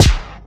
futuristic_stomp.wav